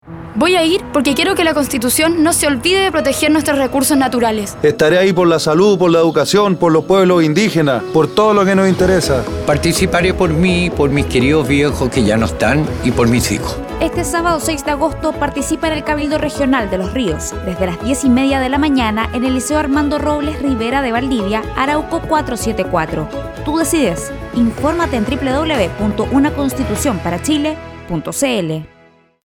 Audio promoción testimonial con detallada información de hora y lugar de realización de los cabildos regionales, Región de Los Ríos 2.